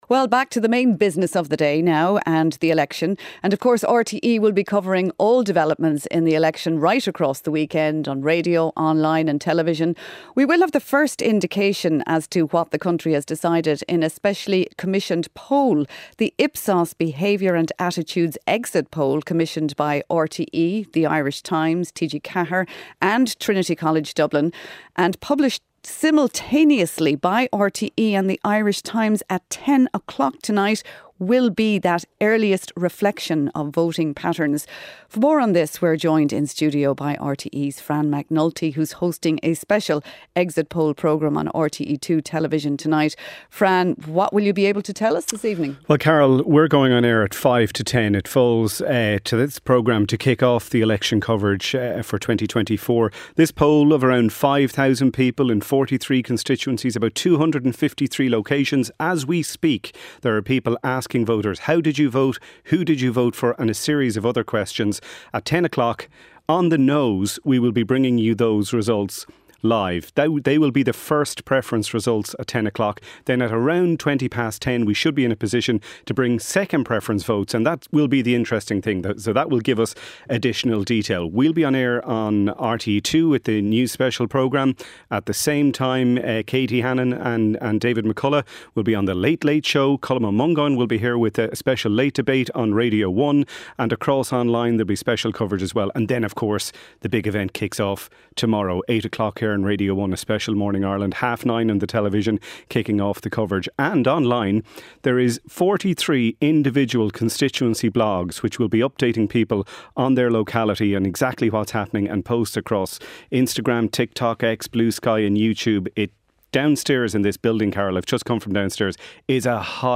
News, sport, business and interviews. Presented by Rachael English. Listen live Monday to Friday at 1pm on RTÉ Radio 1.